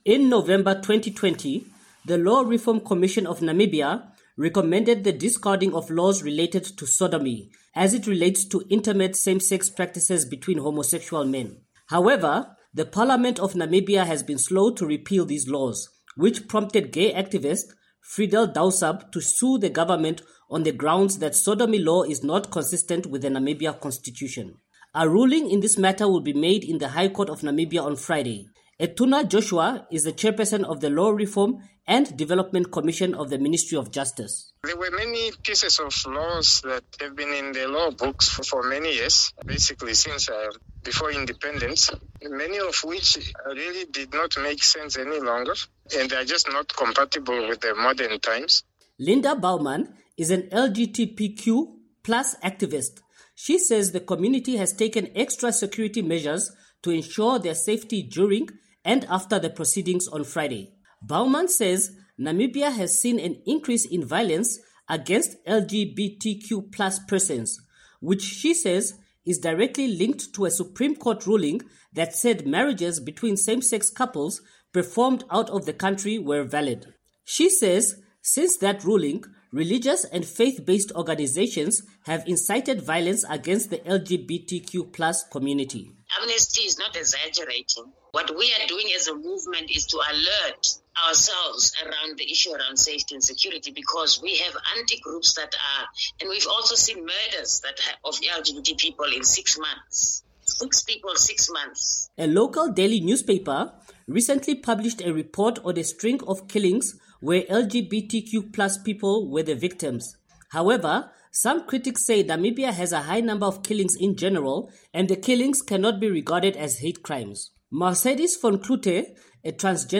reports from Windhoek Namibia